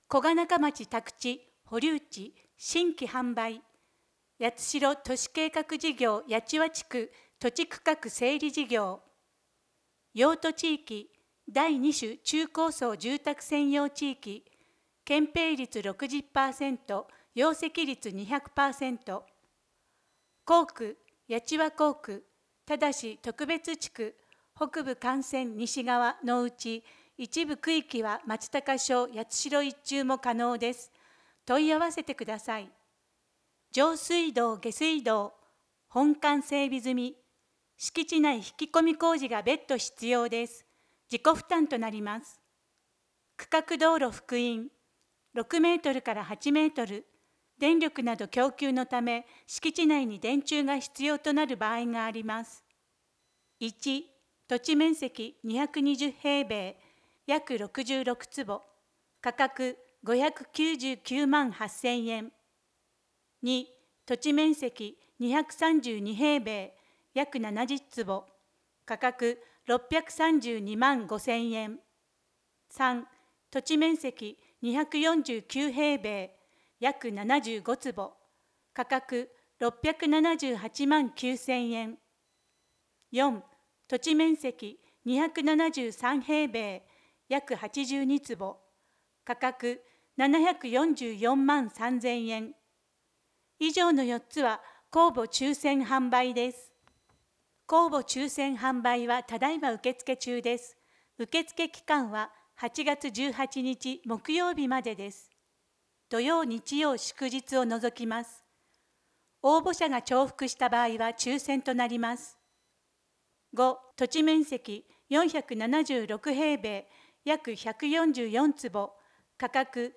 声の市報 はじめに （ファイル：1.1メガバイト） P1 表紙 （ファイル：1.79メガバイト） P2-5 特集 ドコイク？